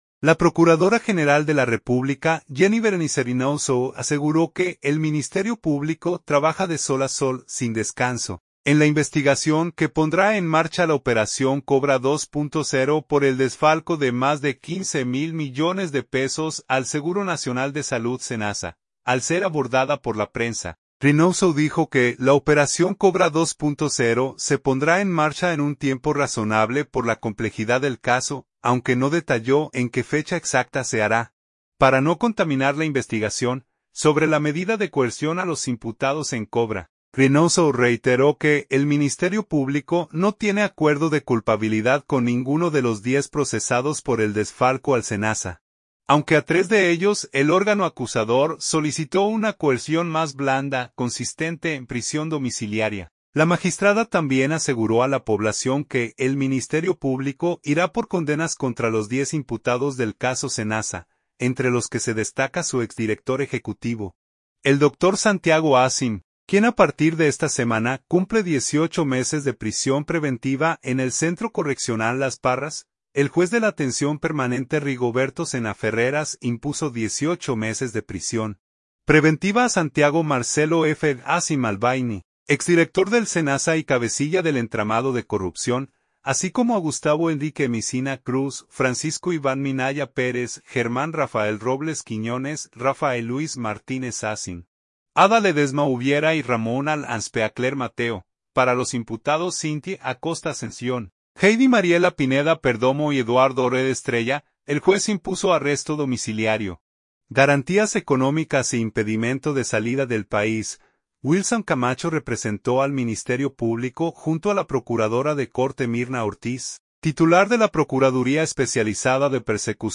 Al ser abordada por la prensa, Reynoso dijo que la operación Cobra 2.0 se pondrá en marcha en un tiempo razonable por la complejidad del caso, aunque no detalló en qué fecha exacta se hará, para no contaminar la investigación.